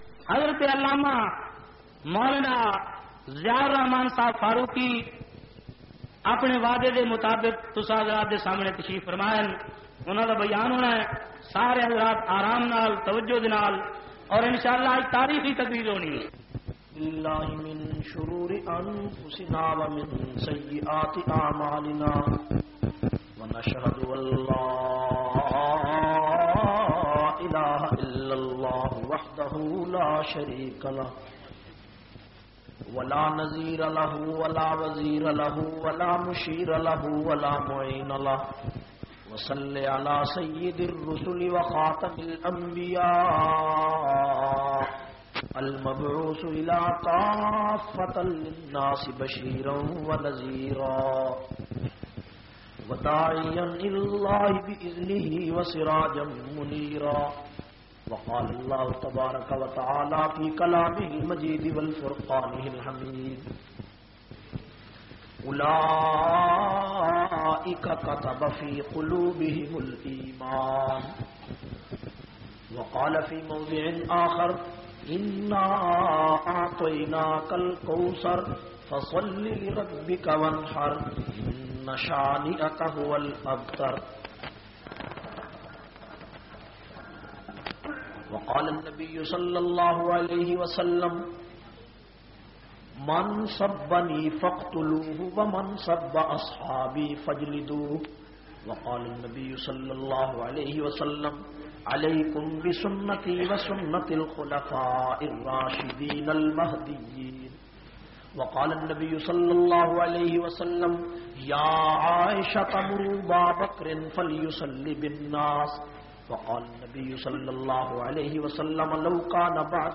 136- Seerat un Nabi s.a.w.w inna aatyna kal kausar tareekhi bayan khushab.mp3